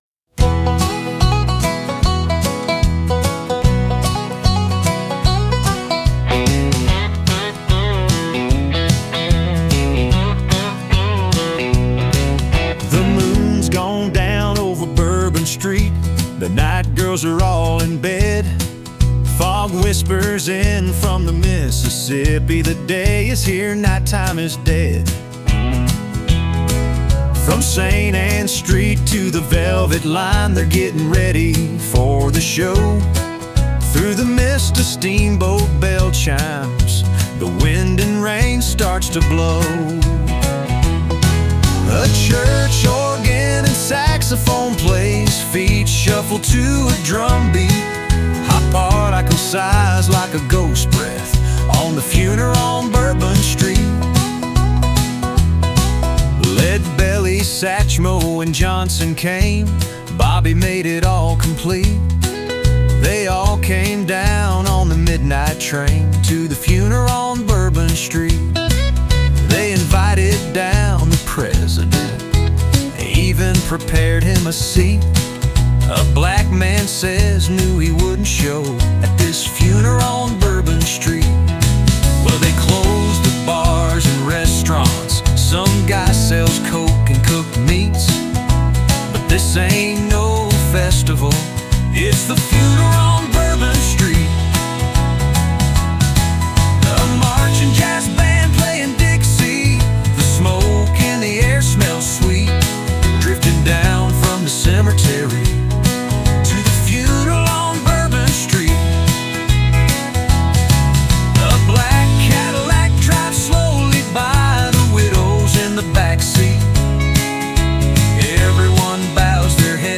richly atmospheric and poetic country ballad